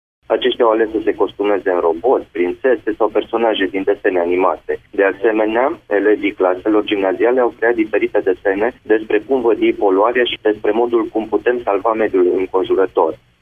Valentin Clonț, viceprimar Făgăraș: